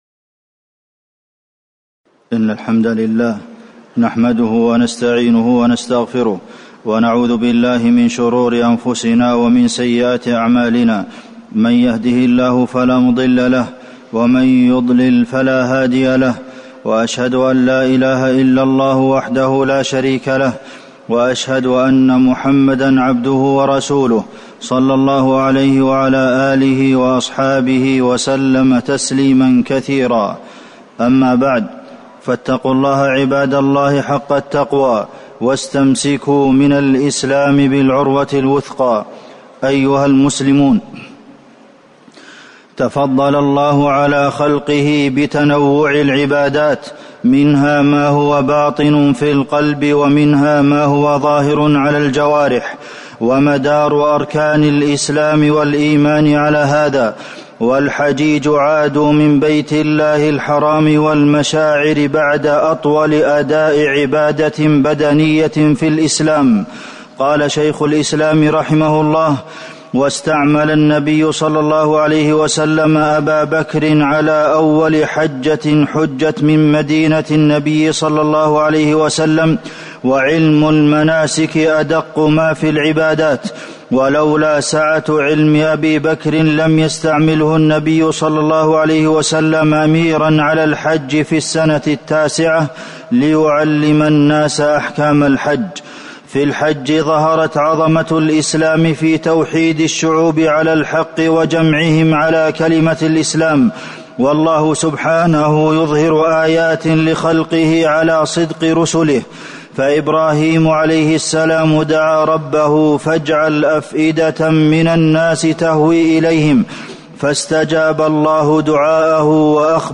تاريخ النشر ٢٢ ذو الحجة ١٤٤٠ هـ المكان: المسجد النبوي الشيخ: فضيلة الشيخ د. عبدالمحسن بن محمد القاسم فضيلة الشيخ د. عبدالمحسن بن محمد القاسم ماذا بعد الحج